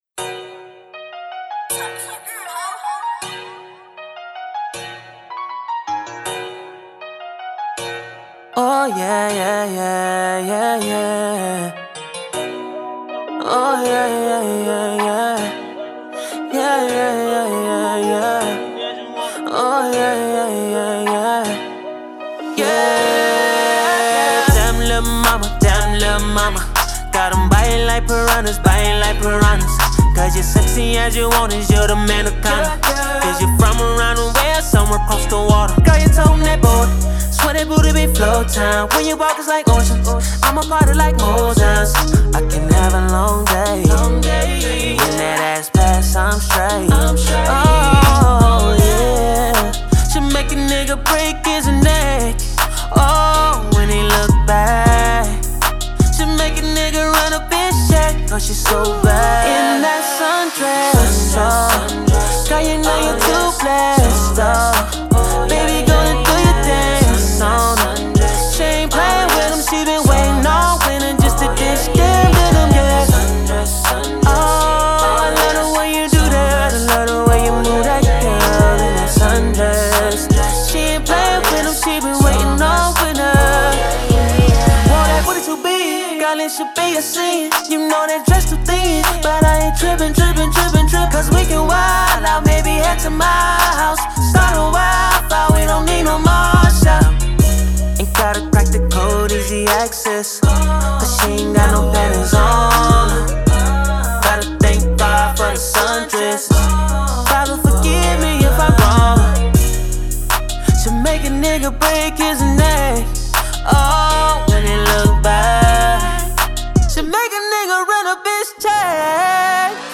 RnB
Upbeat and energetic bound to make you dance
is filled with flattering melodies and witty metaphors